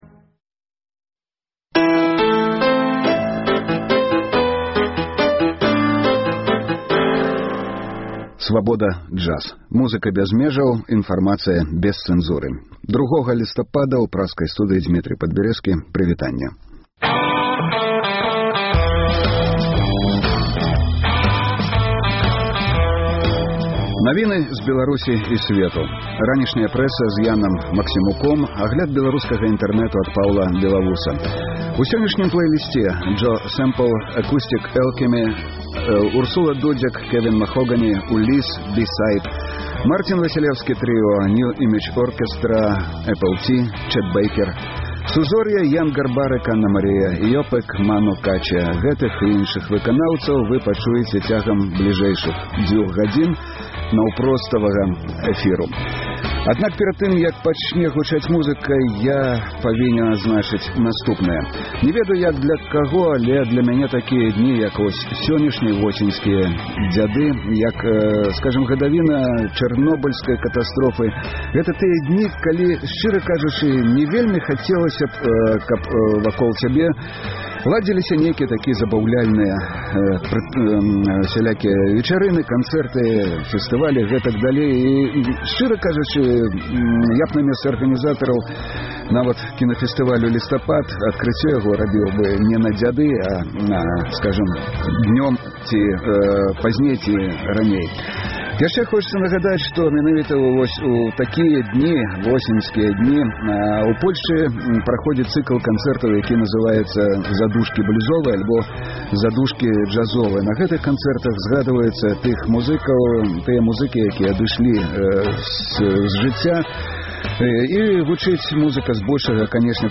Слухайце ад 12:00 да 14:00 жывы эфір Свабоды!